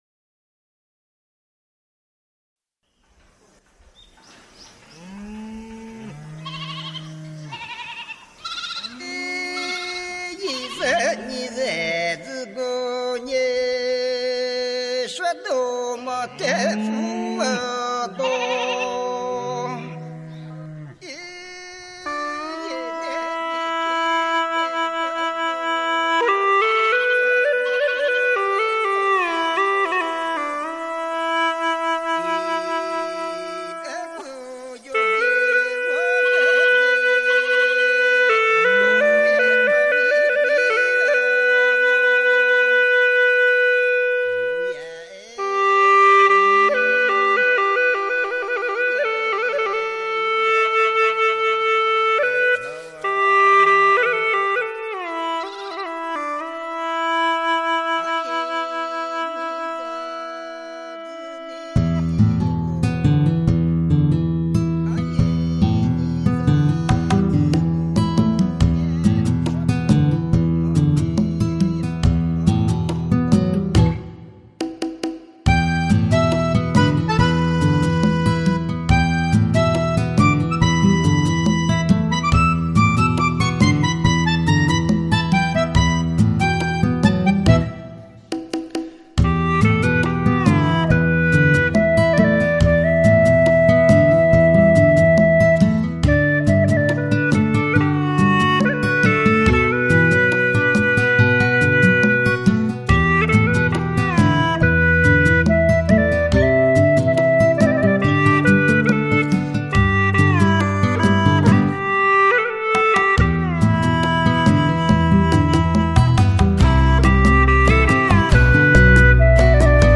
调式 : G 曲类 : 独奏